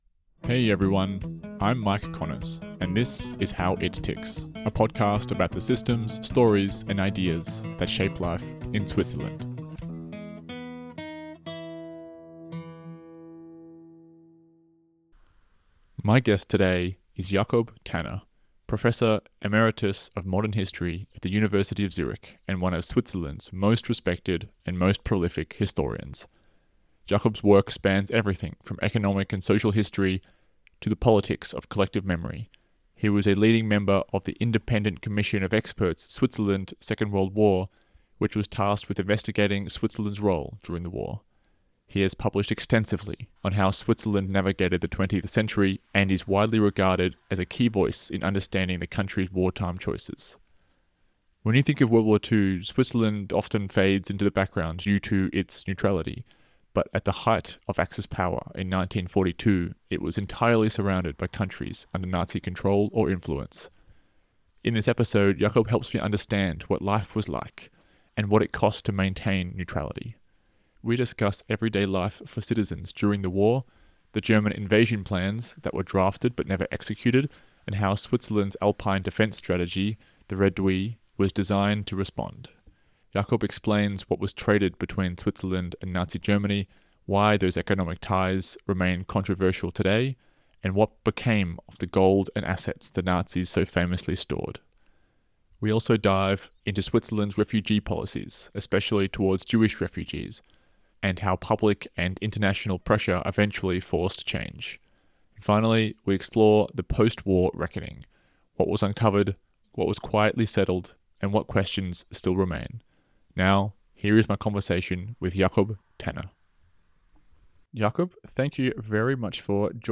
Switzerland’s WWII History | Interview with Jakob Tanner